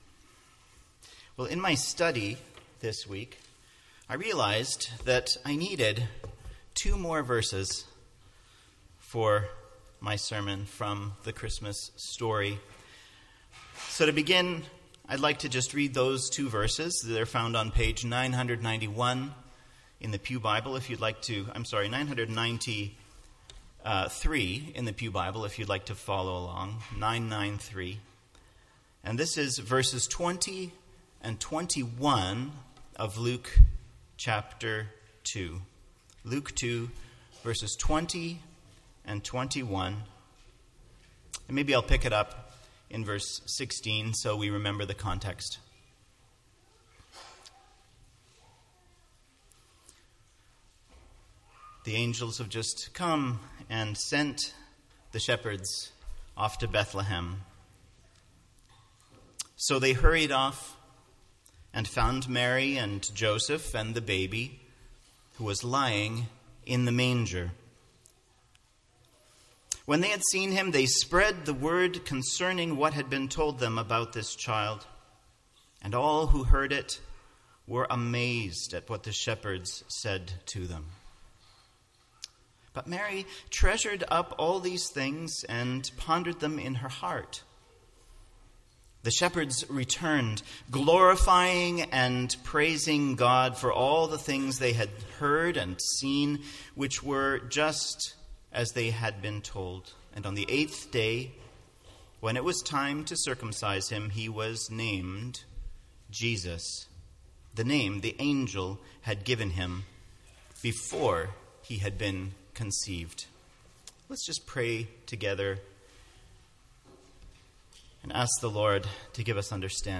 MP3 File Size: 25.1 MB Listen to Sermon: Download/Play Sermon MP3